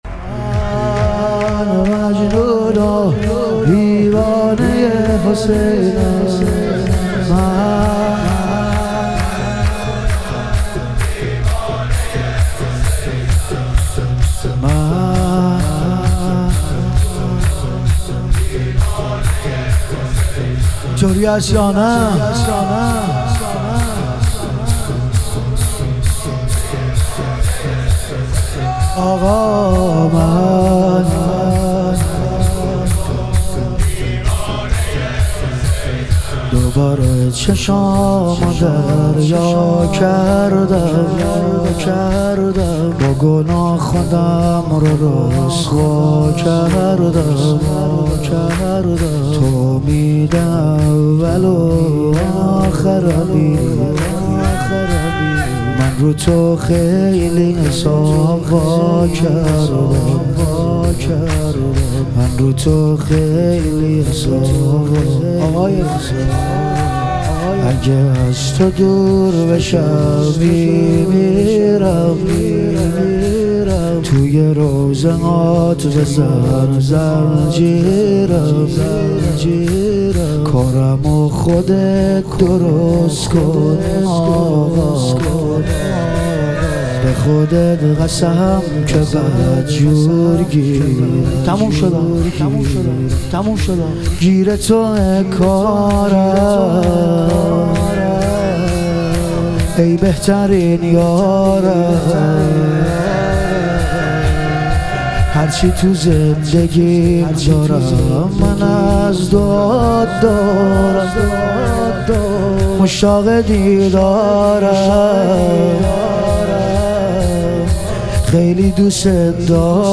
ظهور وجود مقدس امام سجاد علیه السلام - شور